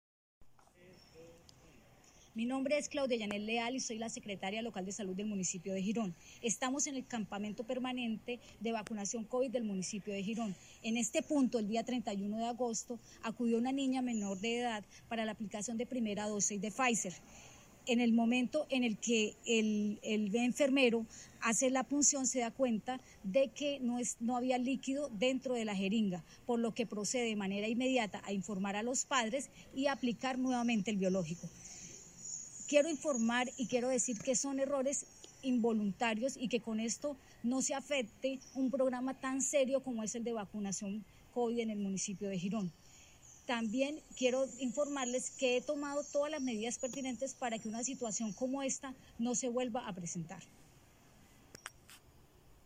SECRETARIA LOCAL DE SALUD, CLAUDIA LEAL.mp3